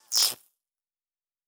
pgs/Assets/Audio/Sci-Fi Sounds/Electric/Spark 03.wav at master
Spark 03.wav